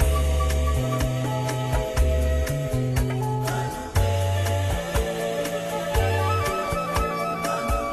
121_AfricanFunk1.wav